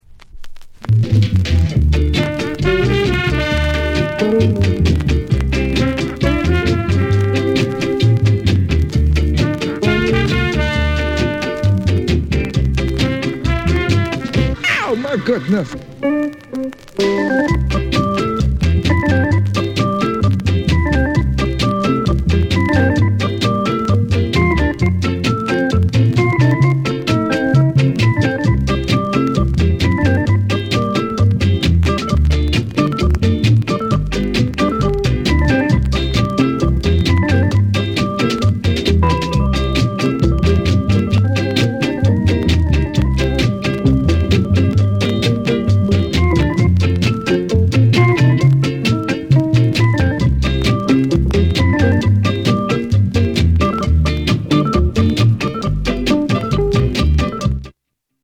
NICE ROCKSTEADY